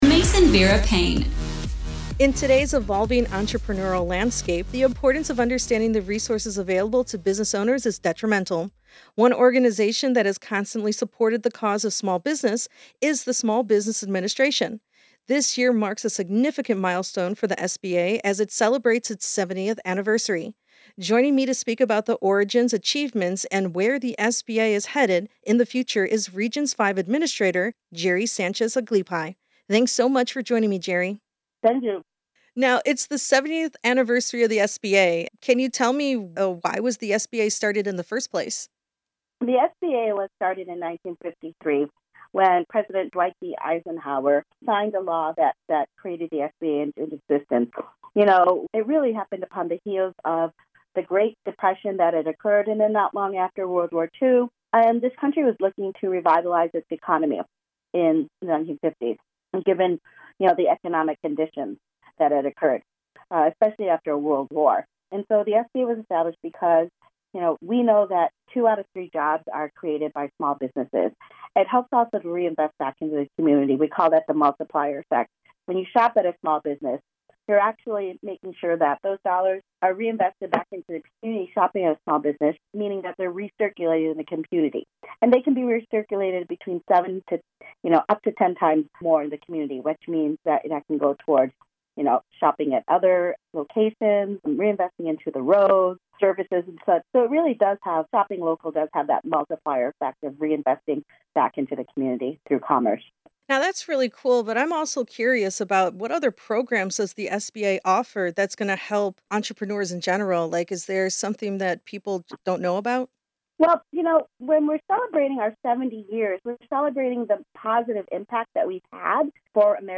SBA Region Five Administrator Geri Aglipay discusses the history of the Small Business Administration